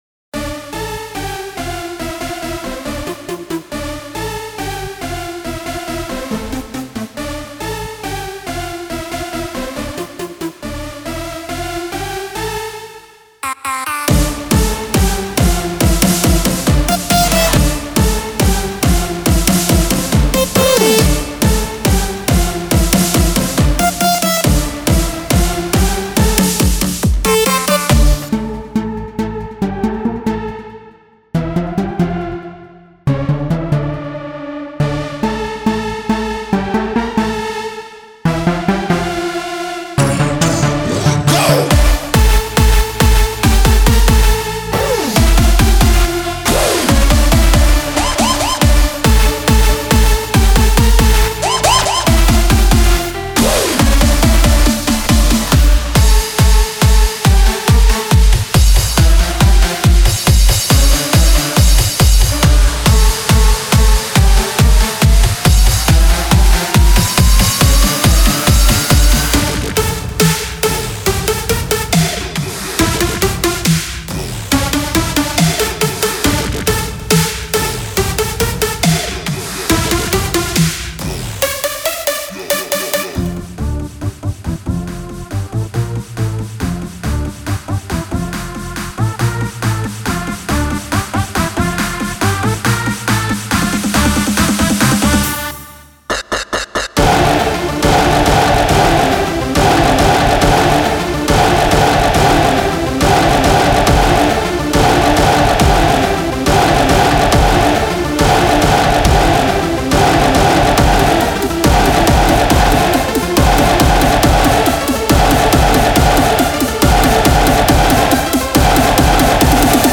מקצבים לקורג